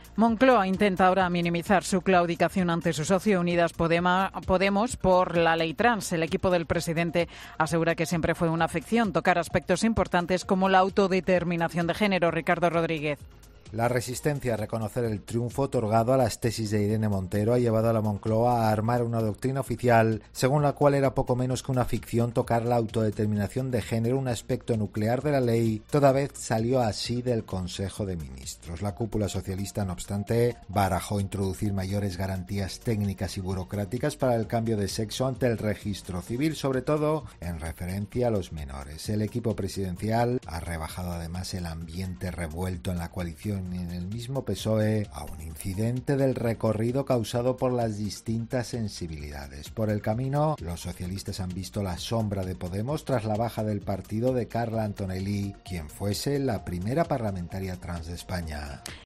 Moncloa intenta minimizar su claudicación ante Unidas Podemos con la 'ley Trans'. Crónica